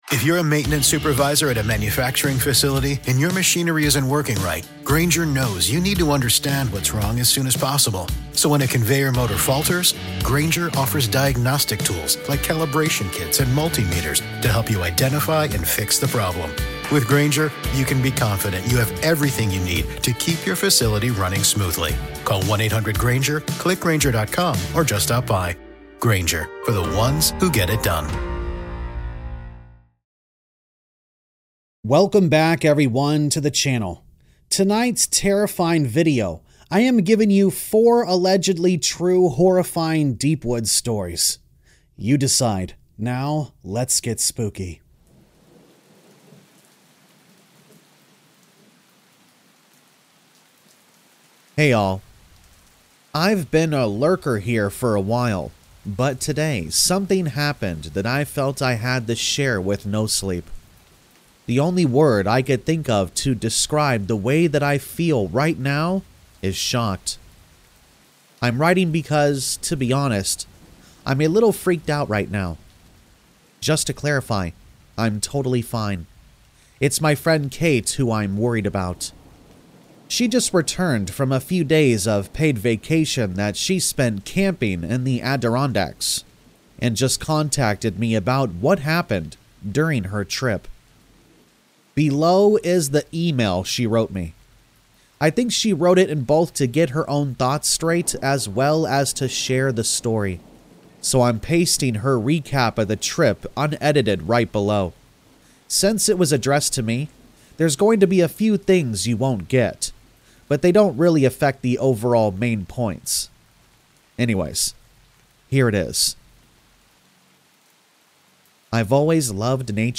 4 TRUE Deep Woods Horror Stories | Fall Asleep To Camping Scary Stories | Rain Sounds